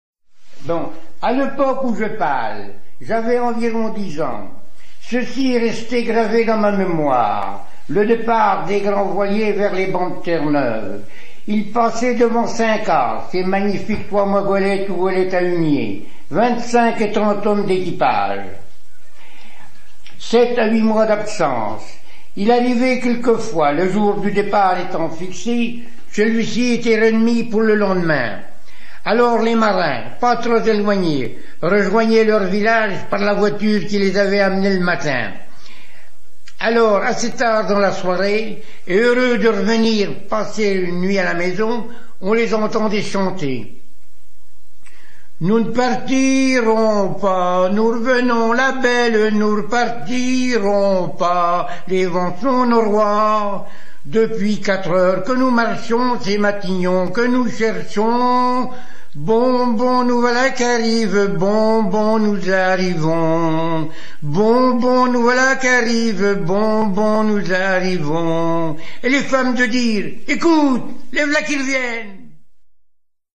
Localisation Saint-Jacut-de-la-Mer
Fonction d'après l'analyste gestuel : à marcher
Genre énumérative
Pièce musicale éditée